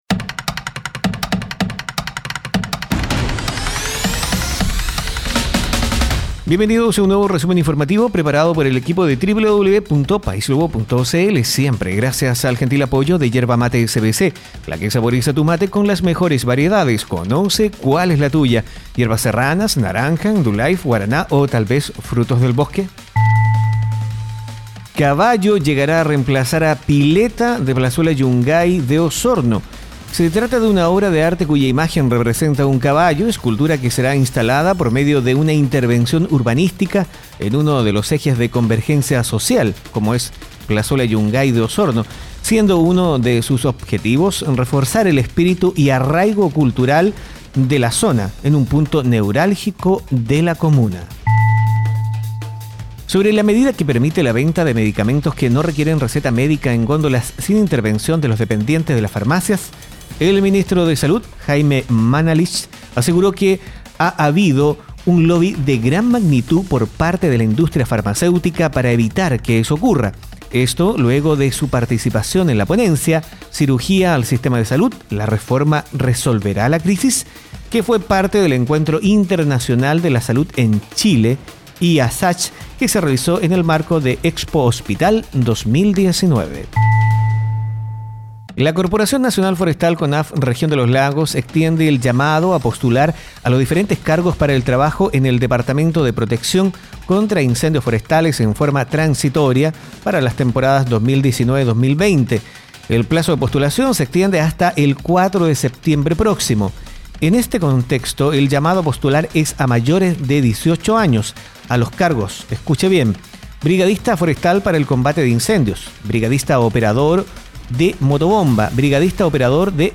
Noticias e informaciones en pocos minutos.